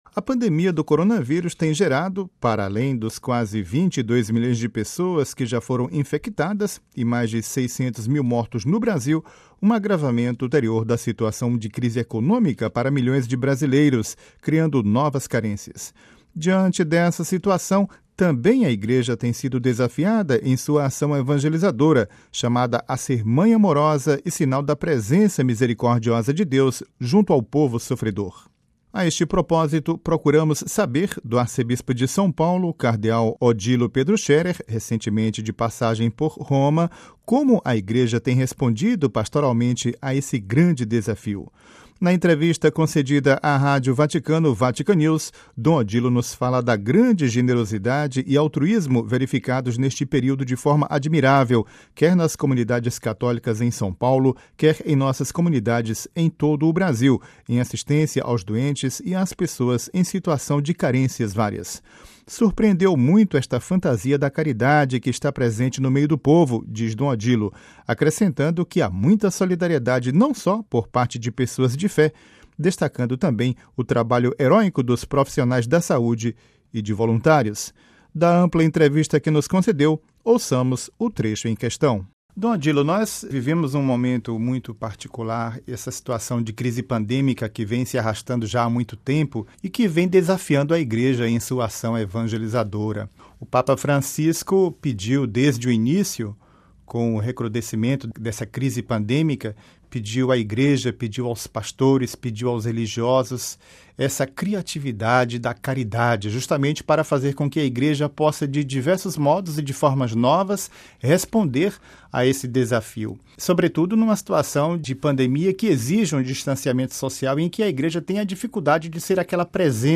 Ouça a entrevista com o cardeal Odilo Pedro Scherer